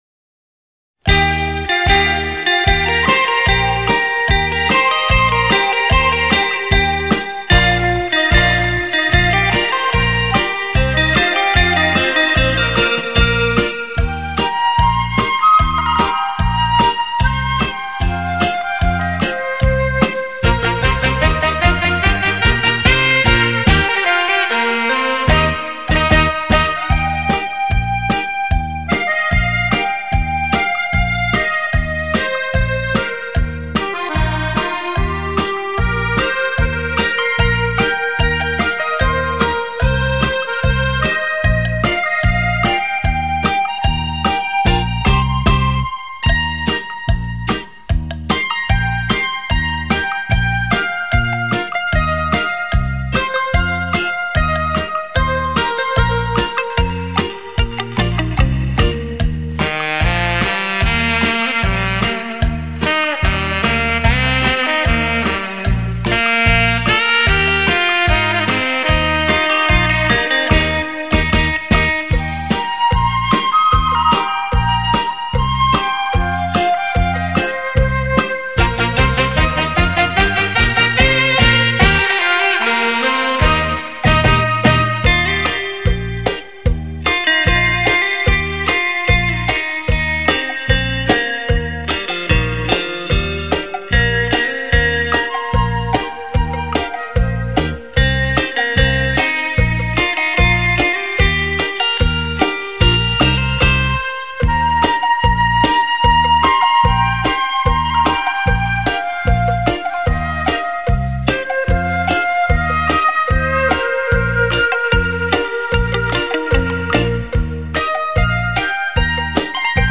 吉鲁巴